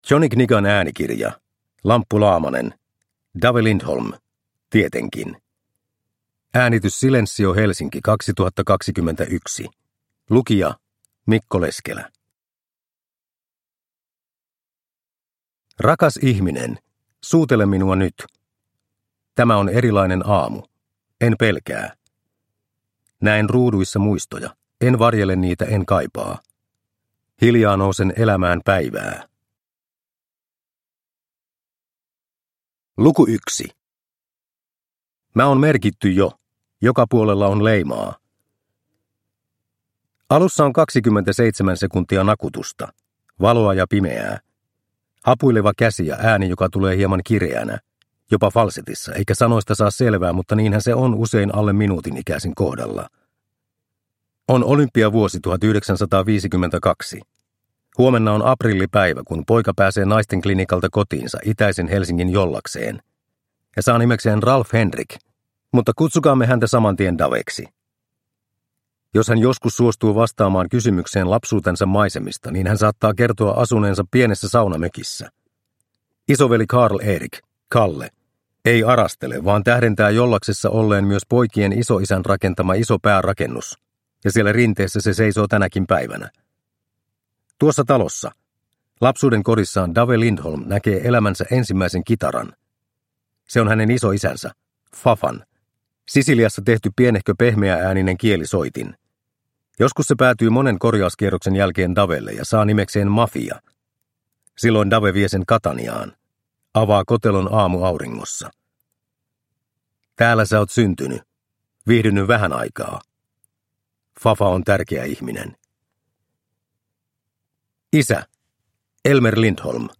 Dave Lindholm – Ljudbok – Laddas ner